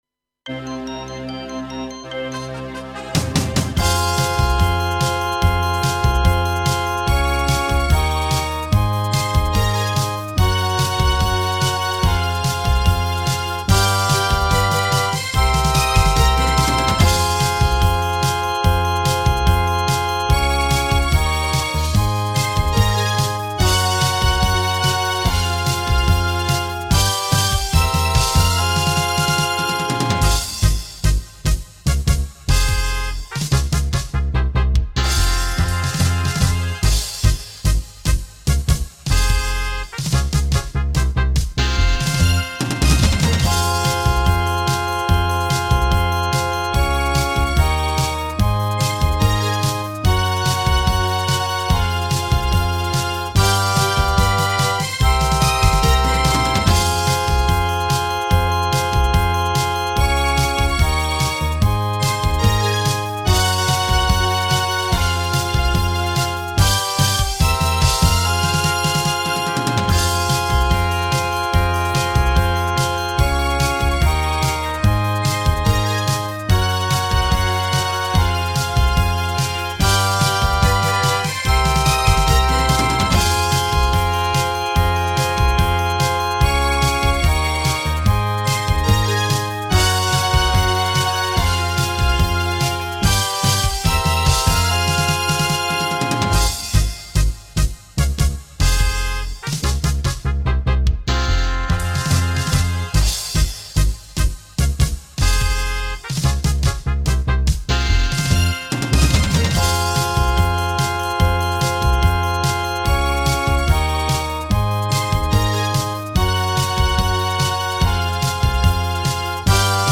Accompagnement seul moyen 148